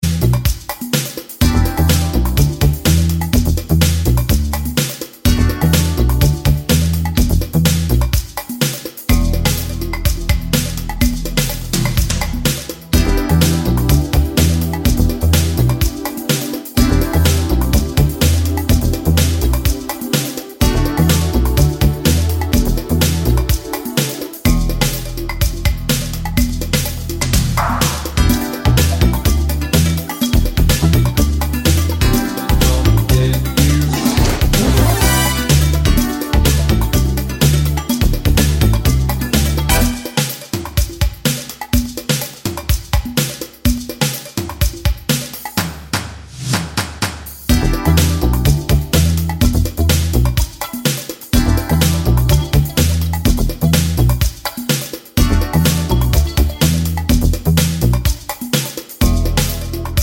Twofers Medley Pop (1980s) 5:07 Buy £1.50